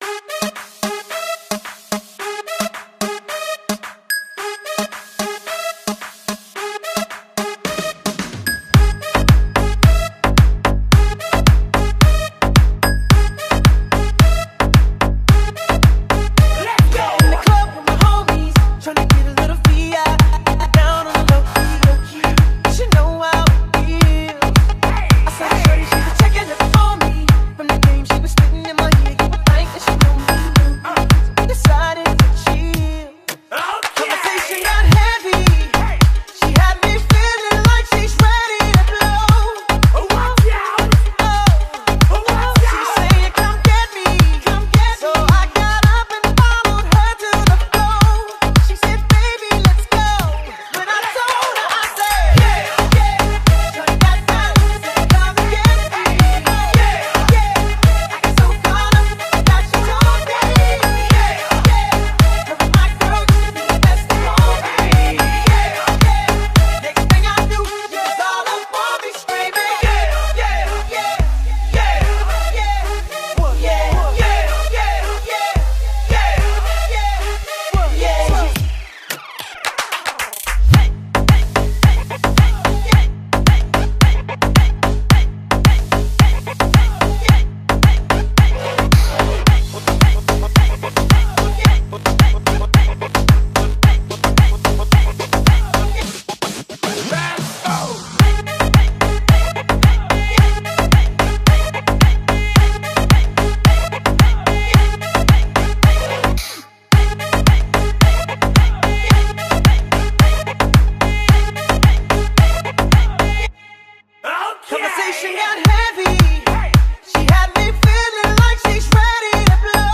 это зажигательный трек в жанре хип-хоп и R&B